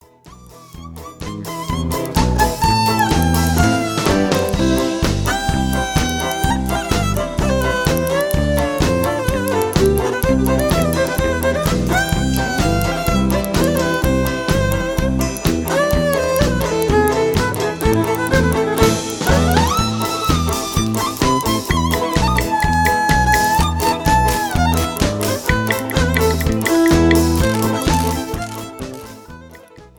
In her warm, deep voice
Folk